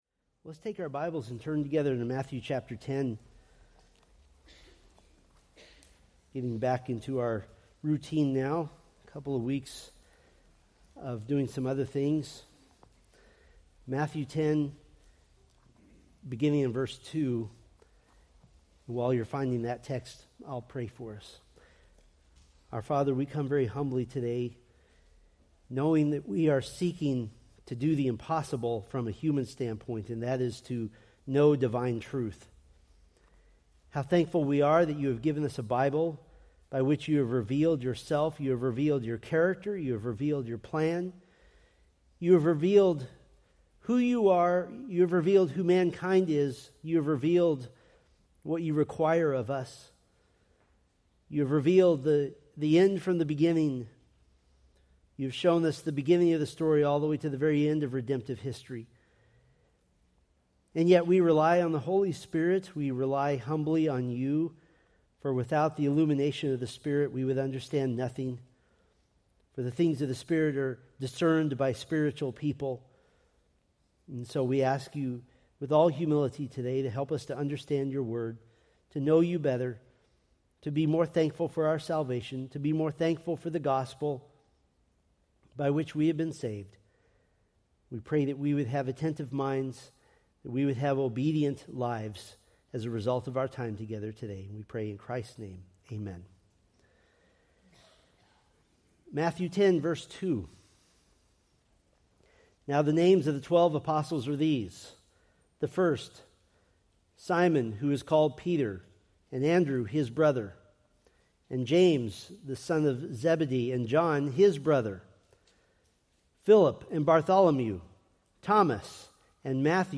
Preached March 23, 2025 from Matthew 10:3